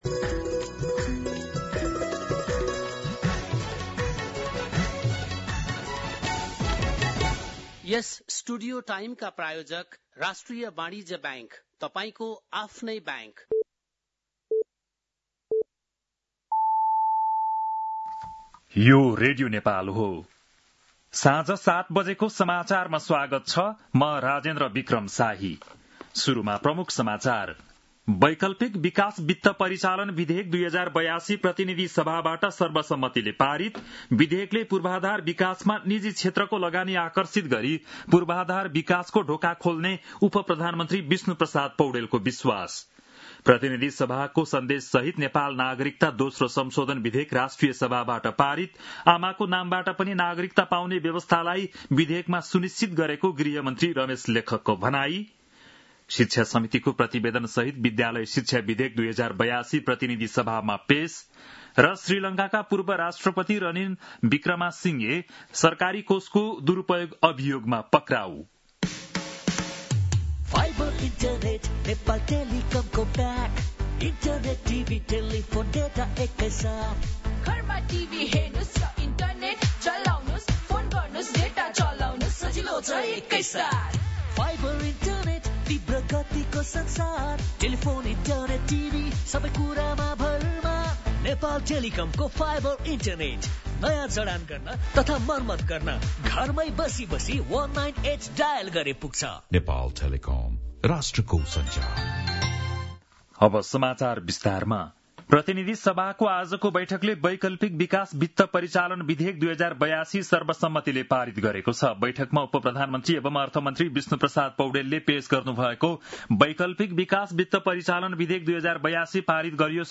बेलुकी ७ बजेको नेपाली समाचार : ६ भदौ , २०८२